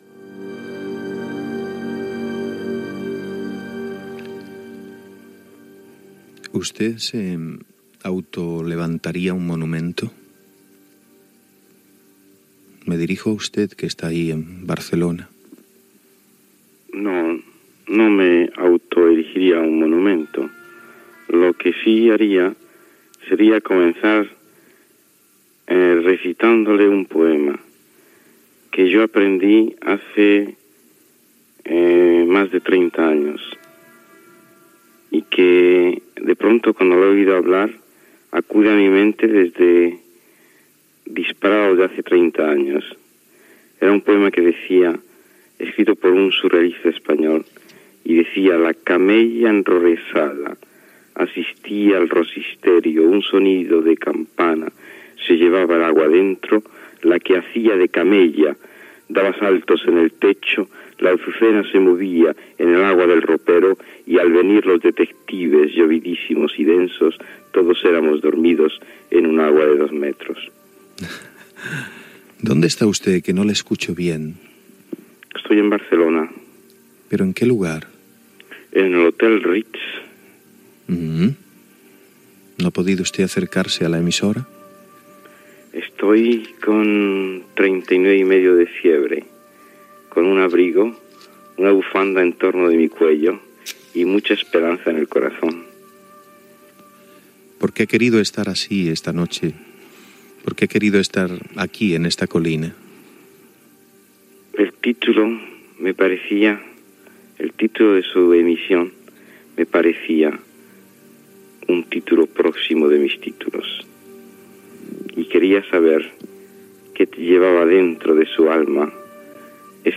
Entrevista a l'escriptor Fernando Arrabal